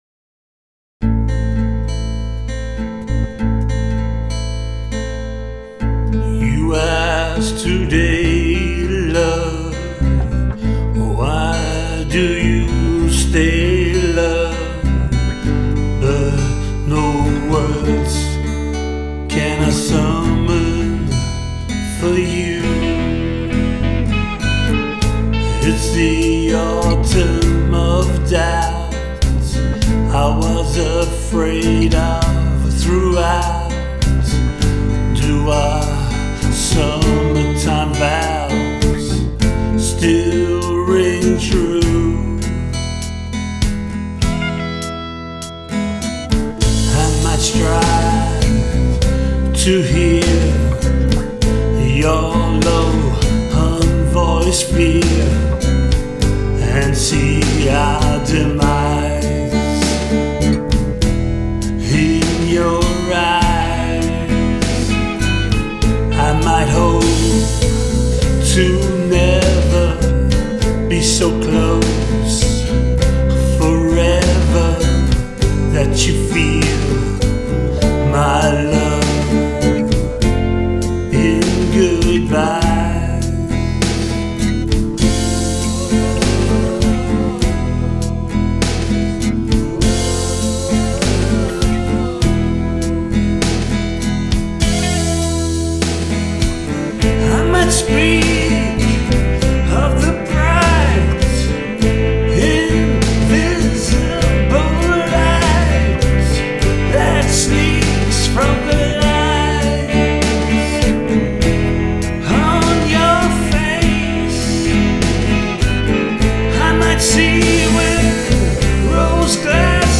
Very Nick Cave…
I personally love a slow brooding tune, especially one with some quality lyrics to chew on along the way.